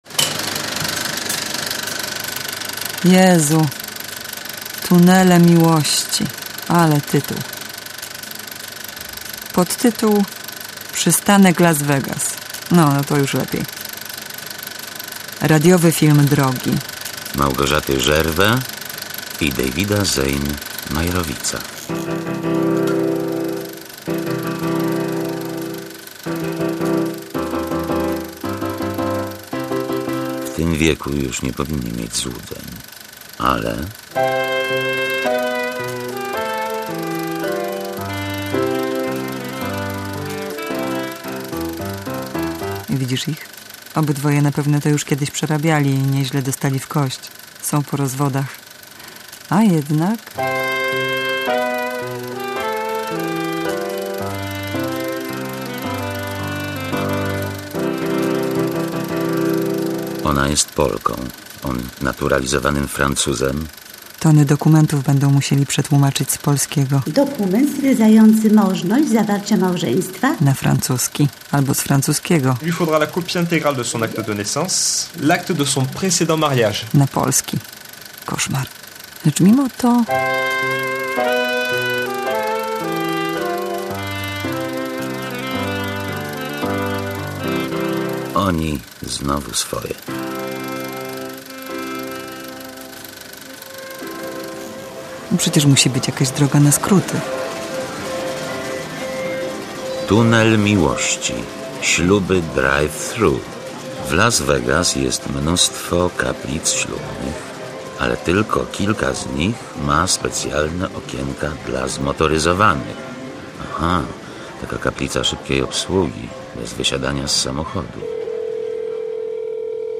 Radiowy film drogi